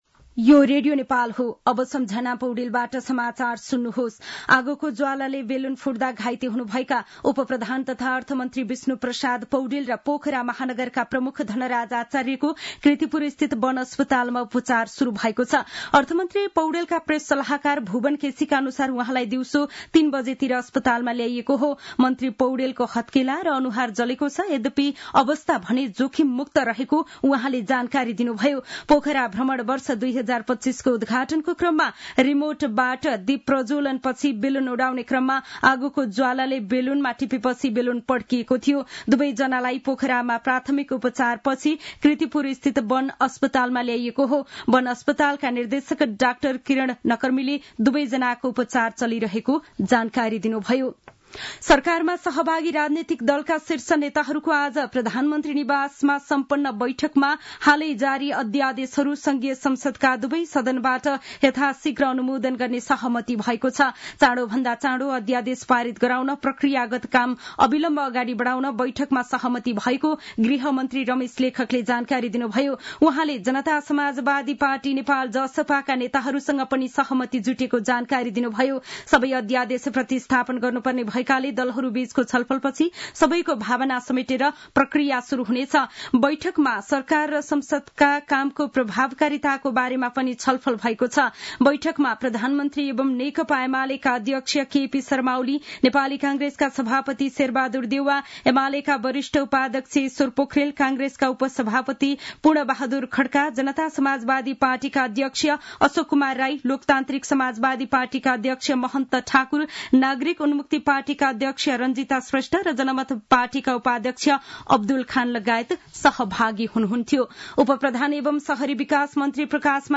साँझ ५ बजेको नेपाली समाचार : ४ फागुन , २०८१